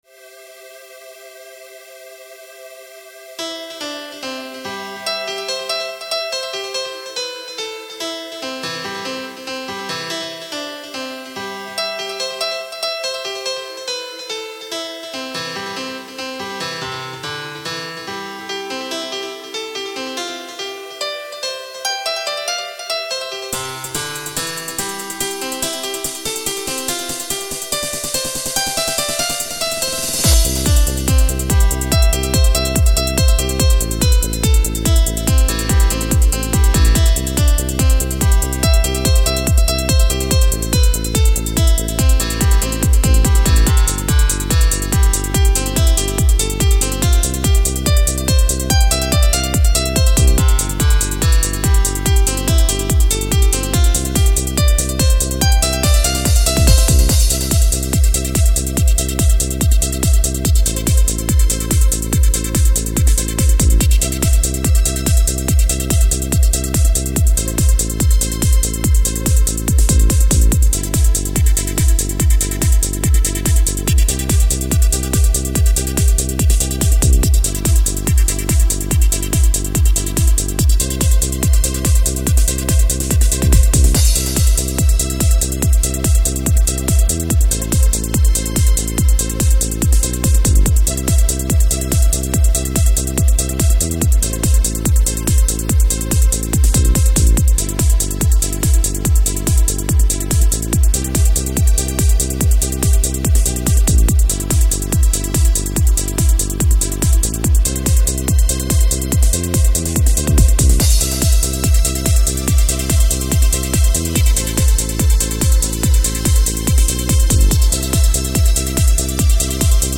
Techno
Trance
Dancehall